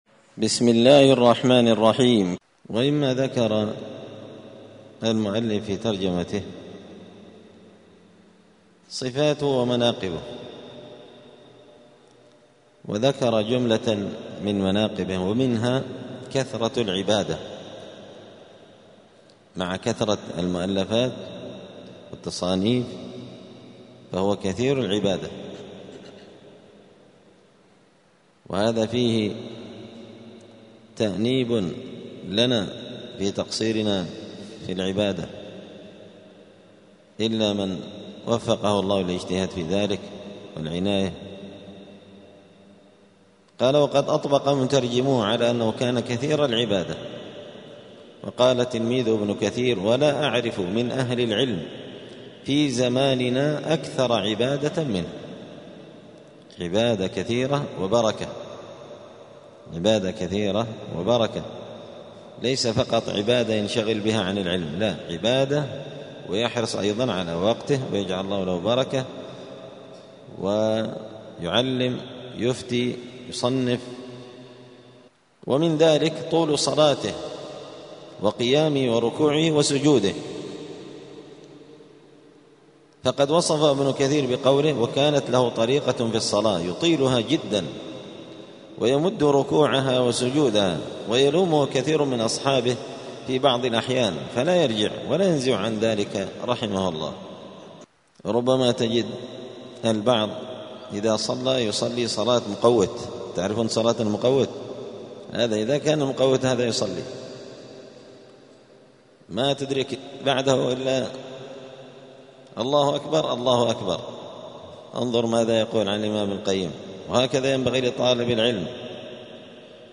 *الدرس الرابع (4) {صفاته ومناقبه}*